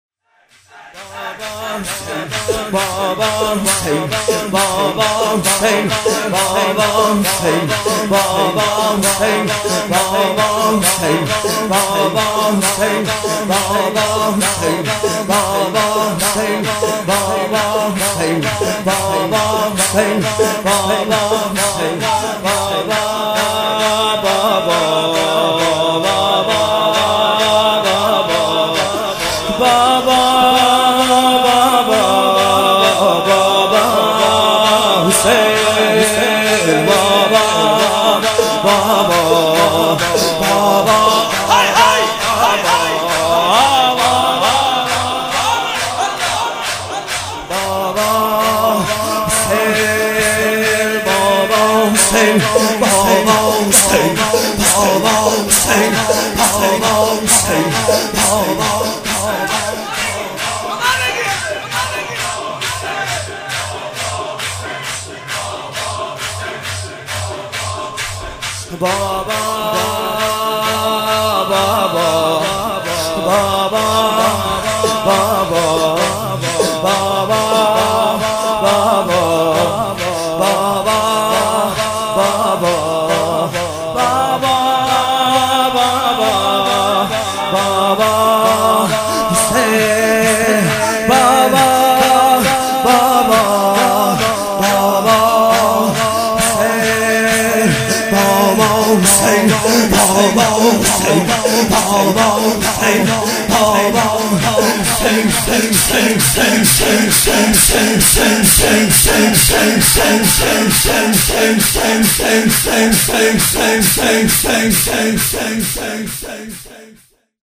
شب 16 محرم 96 - ذکر - بابا حسین